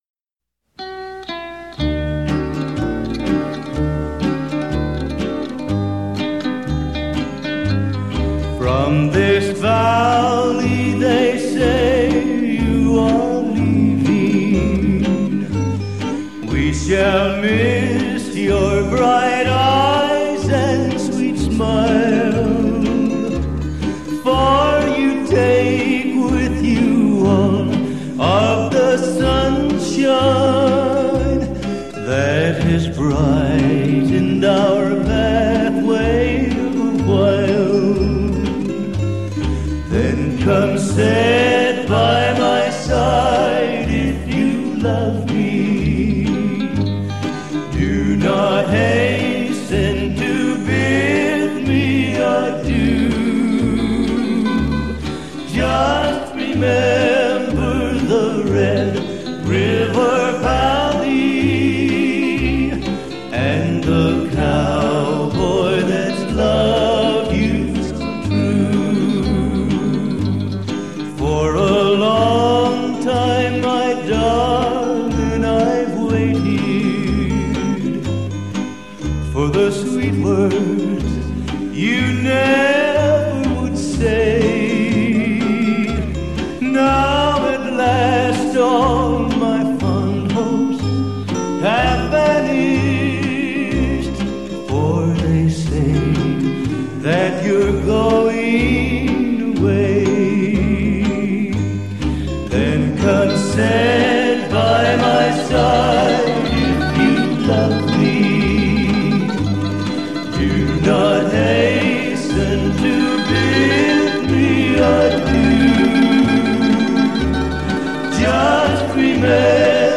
歌曲曲调优美，朗朗上口，也许您可以惬意地哼上几句！